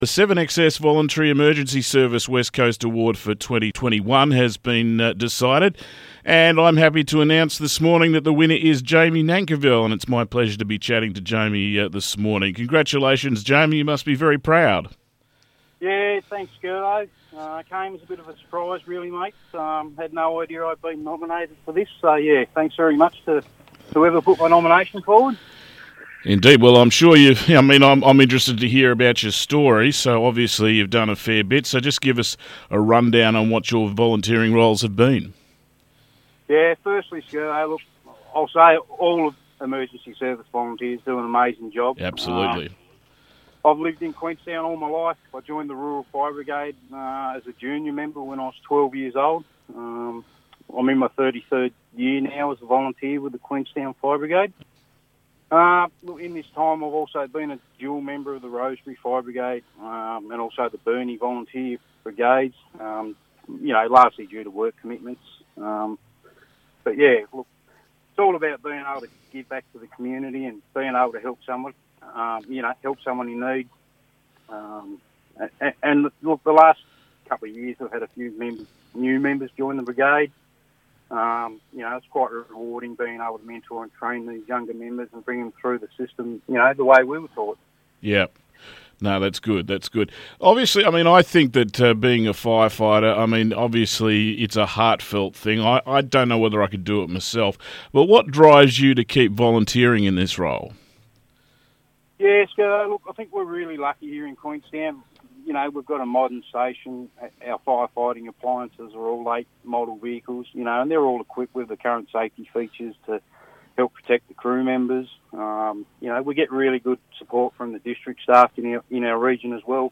Interview with this year's 7XS West Coast Volunteer Emergency Service Awards